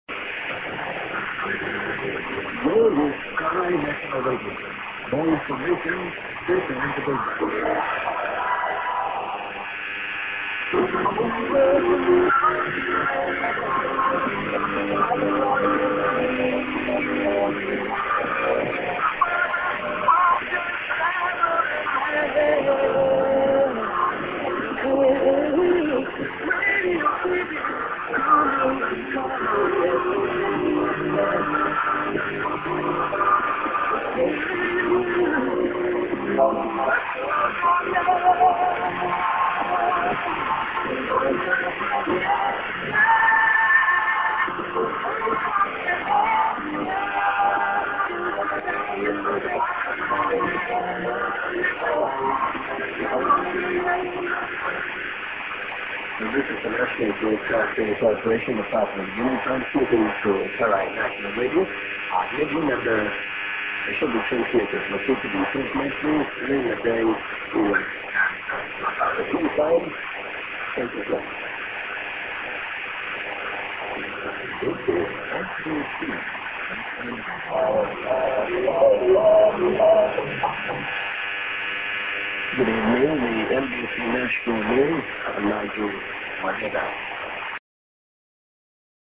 ->ID(man)->music->ID(man)->ID:NBC(man)->SJ->ID(man)